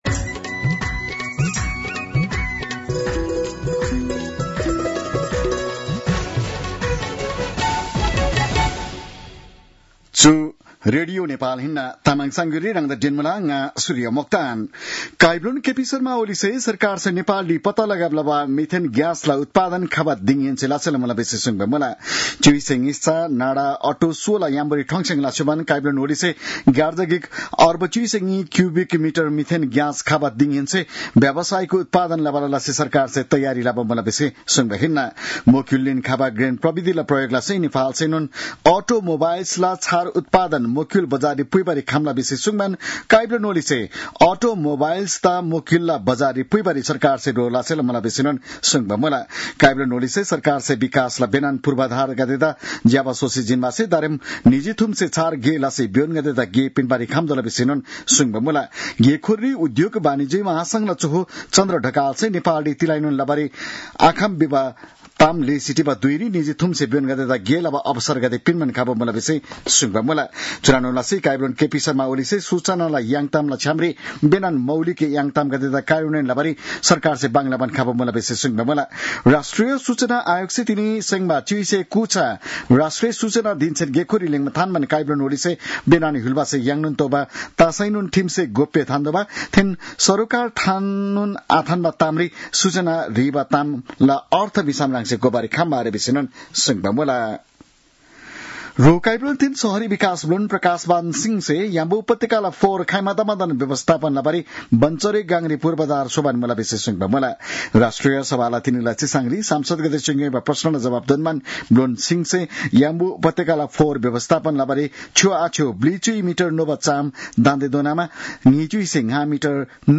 तामाङ भाषाको समाचार : ३ भदौ , २०८२